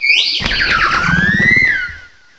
sovereignx/sound/direct_sound_samples/cries/fezandipiti.aif at master